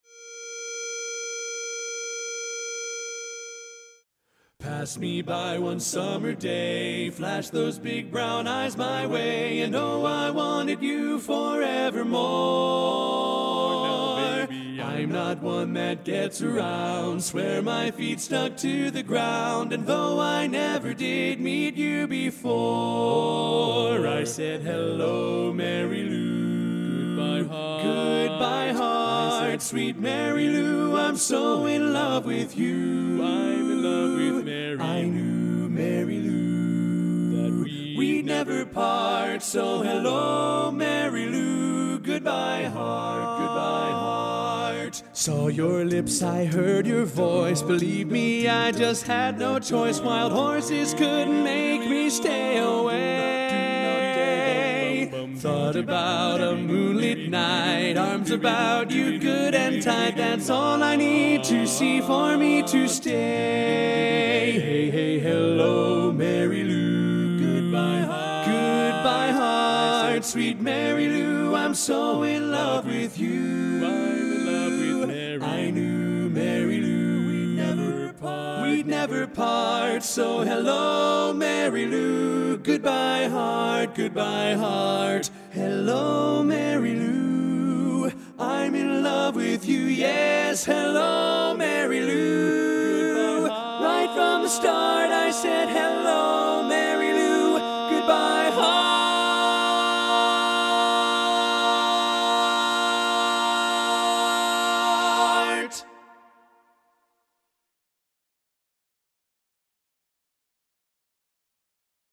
Kanawha Kordsmen (chorus)
Up-tempo
B♭ Major
Lead